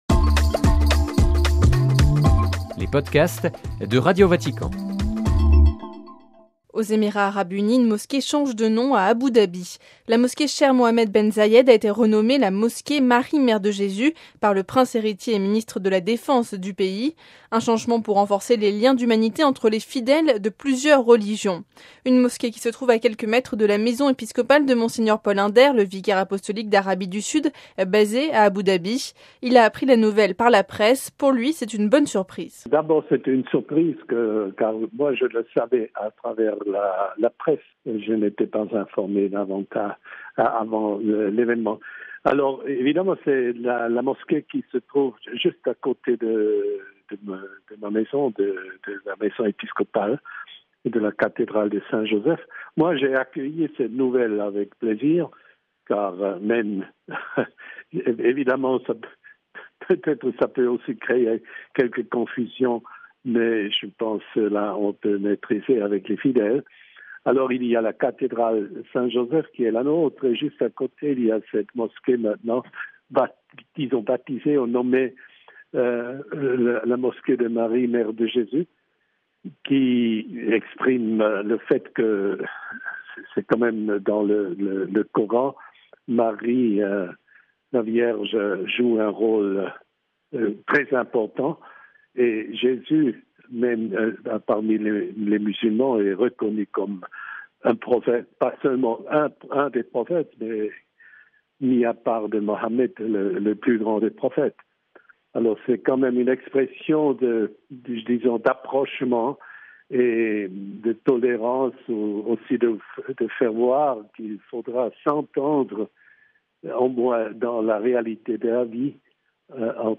(RV) Entretien – Aux Émirats Arabes Unis, une mosquée change de nom à Abou Dhabi: la mosquée "Sheikh Mohammed ben Zayed" a été renommé la mosquée "Marie mère de Jésus" par le prince héritier et ministre de la Défense du pays.